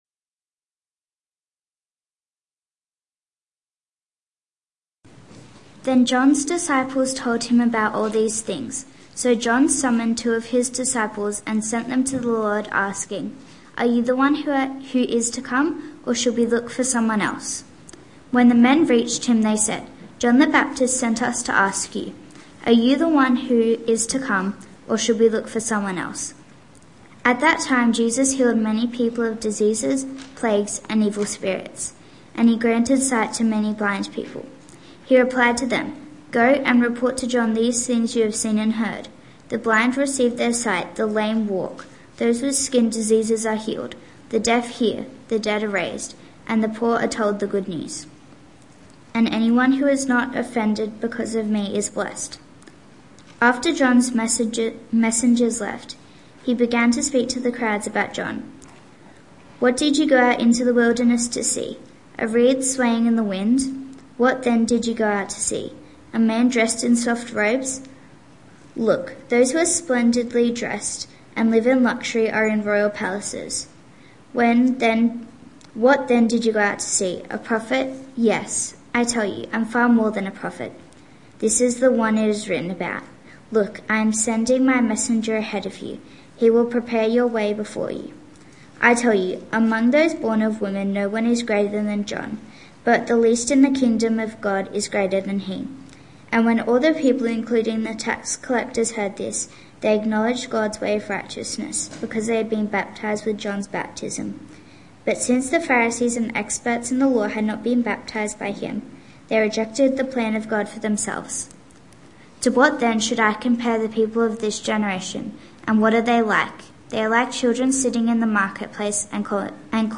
Youth Church